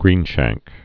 (grēnshăngk)